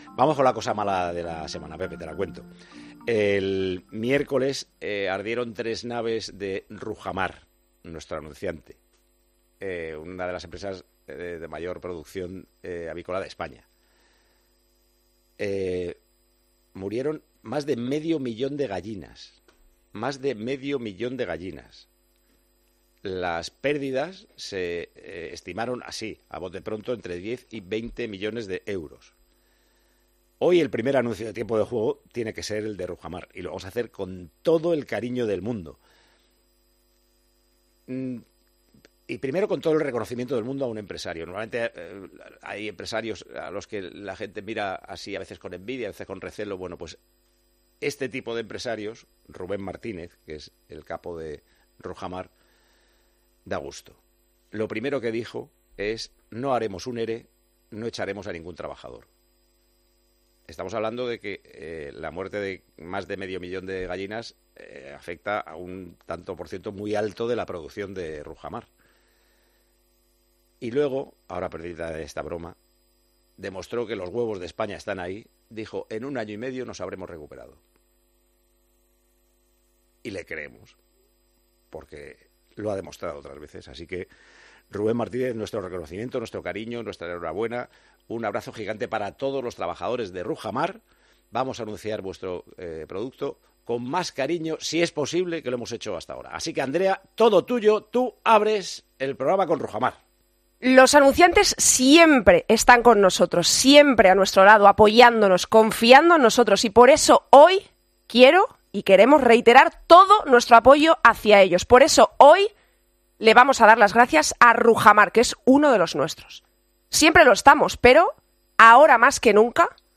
El director y presentador de Tiempo de Juego quiso lanzar un mensaje de apoyo a la empresa tras la trágica noticia ocurrida esta semana.
Paco González dedica siempre unas palabras a Pepe Domingo Castaño en el comienzo de los programas los fines de semana, y este sábado no podía ser menos.